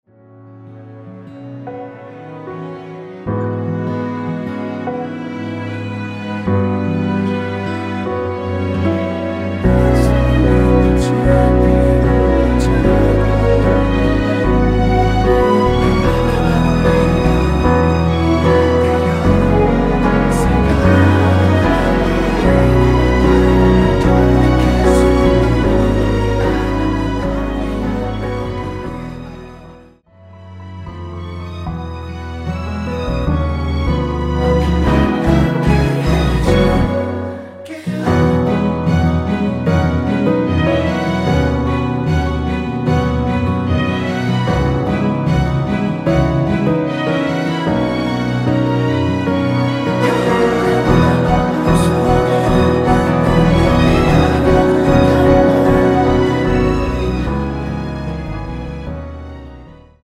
원키에서(-2)내린 코러스 포함된 MR입니다.(미리듣기 확인)
◈ 곡명 옆 (-1)은 반음 내림, (+1)은 반음 올림 입니다.
앞부분30초, 뒷부분30초씩 편집해서 올려 드리고 있습니다.